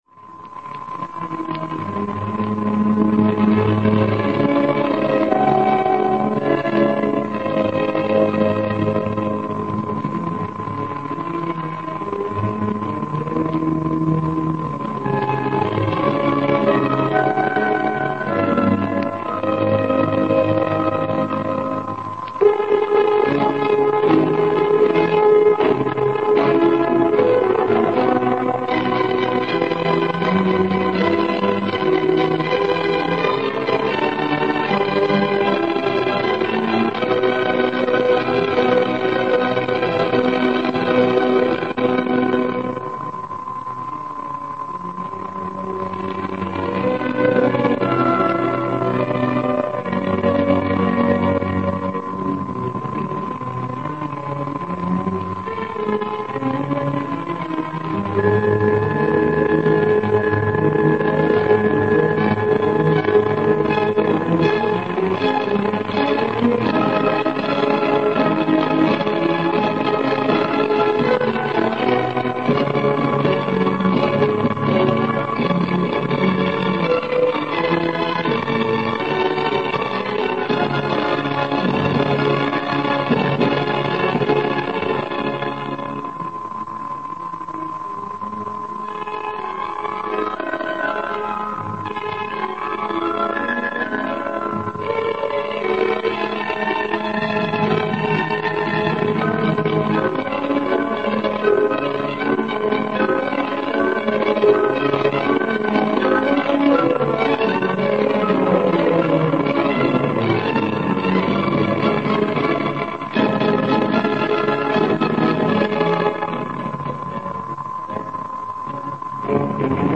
Zene